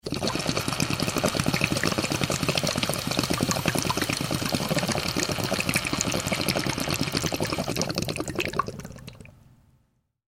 Звук лопающихся пузырей в бонге при затяжке дымом